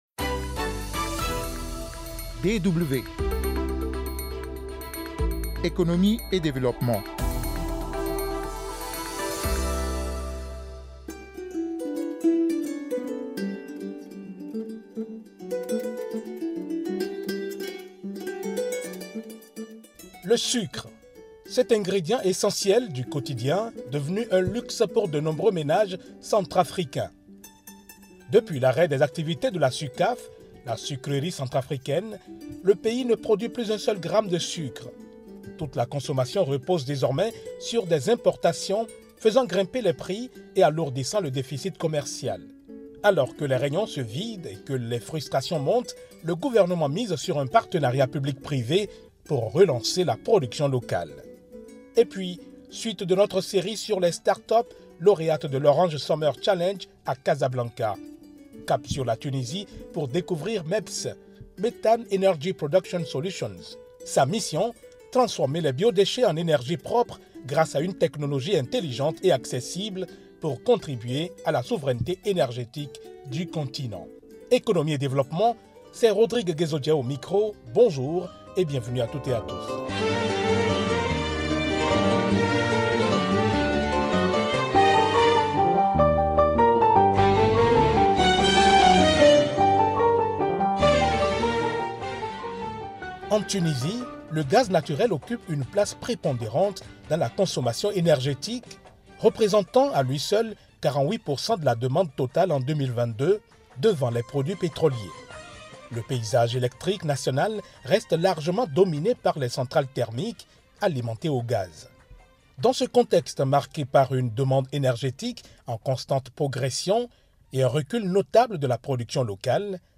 Des analyses, des interviews et des reportages pour comprendre les évolutions actuelles, en Afrique et ailleurs.